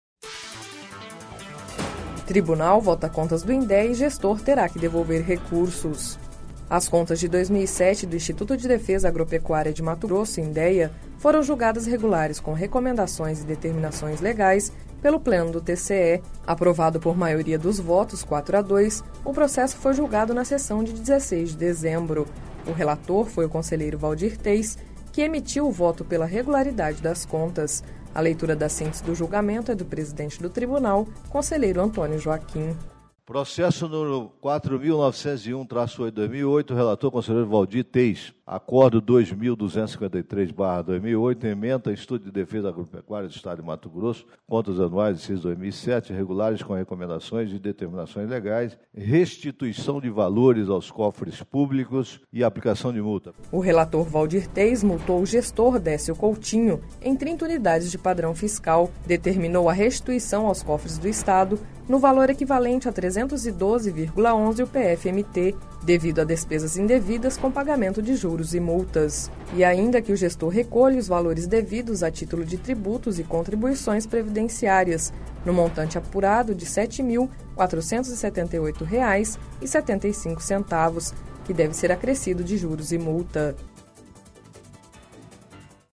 A leitura da síntese do julgamento é do presidente do Tribunal, conselheiro Antonio Joaquim.